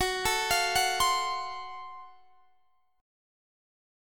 Listen to GbmM7b5 strummed